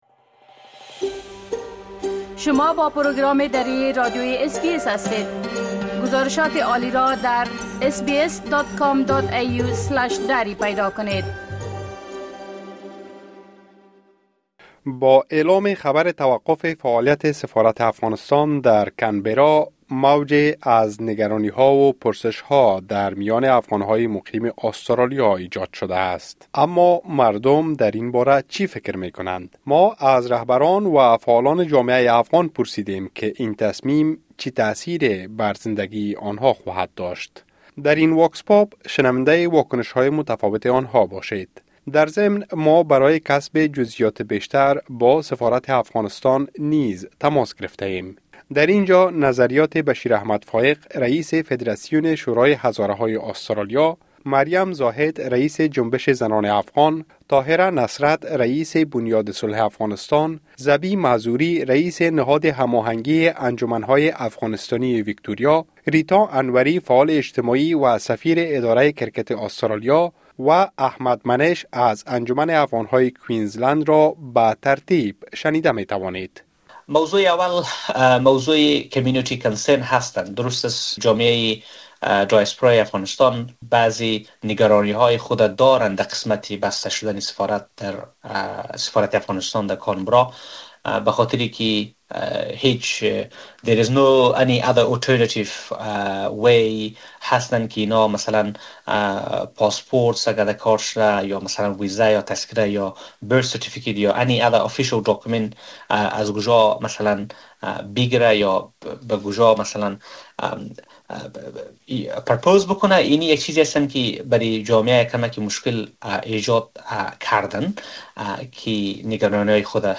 ما از رهبران و فعالان جامعه افغان پرسیدیم که این تصمیم چه تاثیری بر زندگی آن‌ها خواهد داشت. در این واکس‌پاپ، شنونده‌ای واکنش‌های متفاوت آن‌ها باشید.